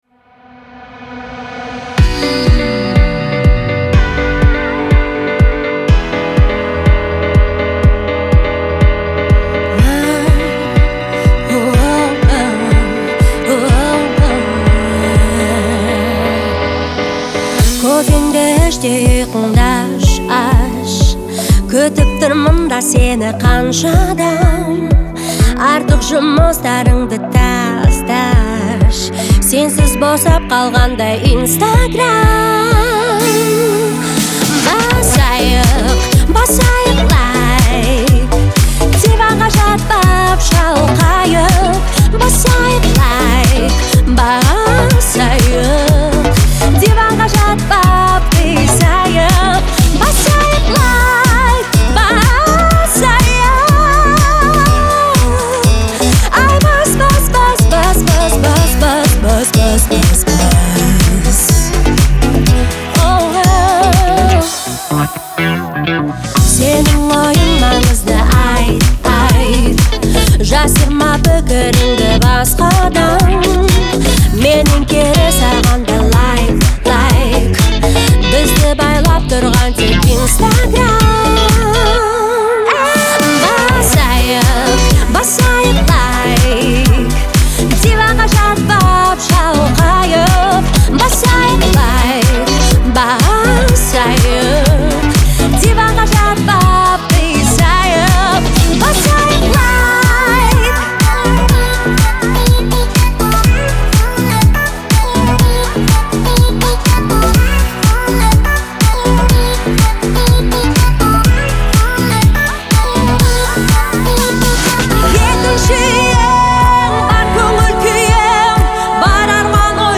это яркая и энергичная песня в жанре поп